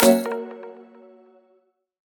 melodic-5.wav